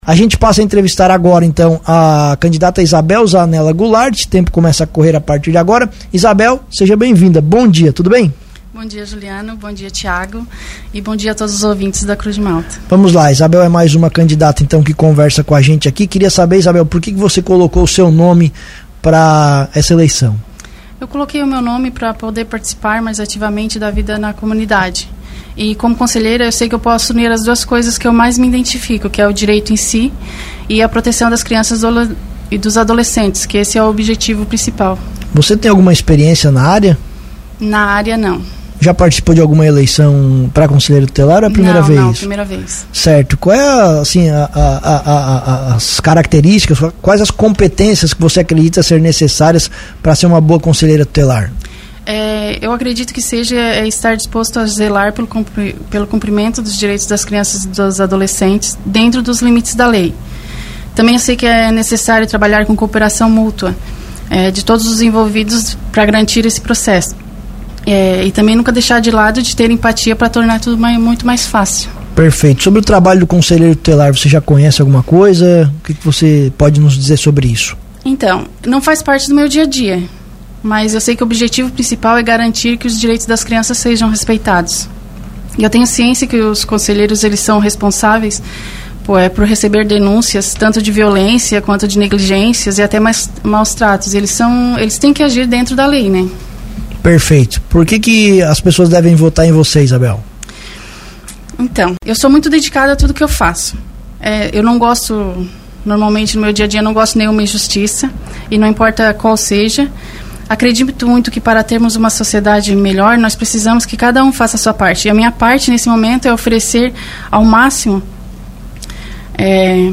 As entrevistas vão ao ar todos os dias a partir das 8h, com tempo máximo de 10 minutos cada.